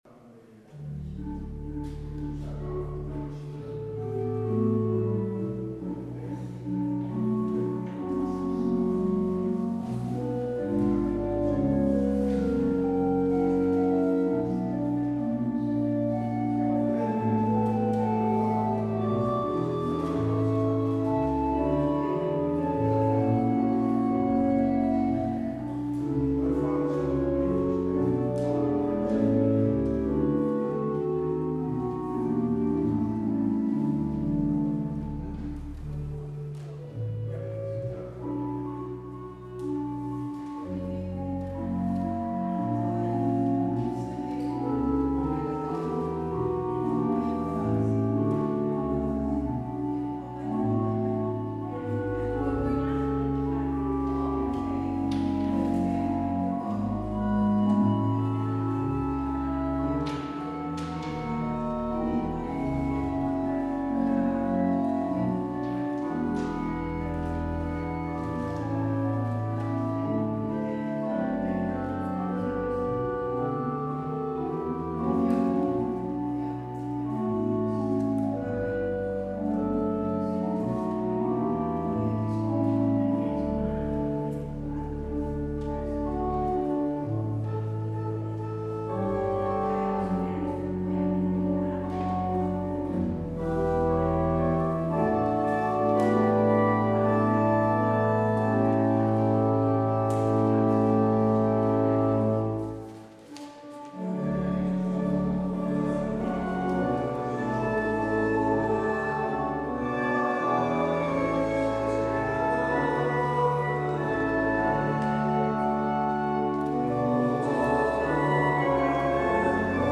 Het openingslied is Lied 1008: 1 en 3 Rechter in het licht verheven. Als slotlied hoort u Lied 978: 1 en 4 Aan U behoort o Heer der Heren.